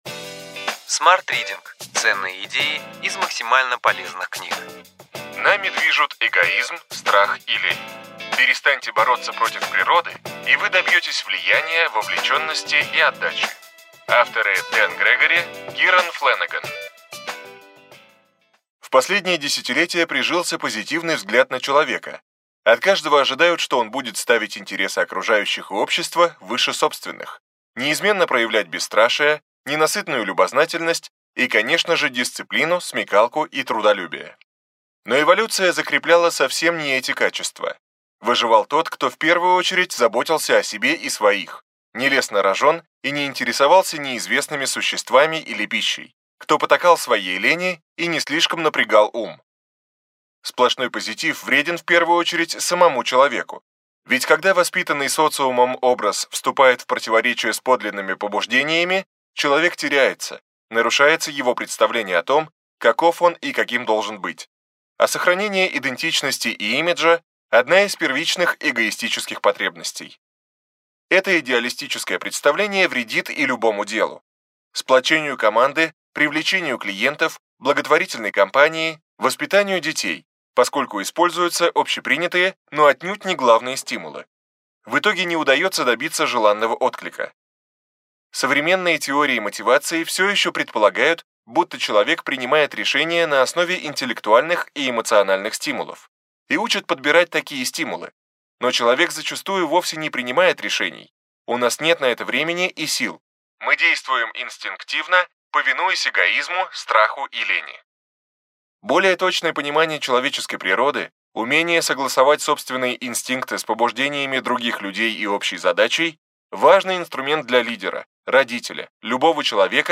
Аудиокнига Ключевые идеи книги: Нами движут эгоизм, страх и лень.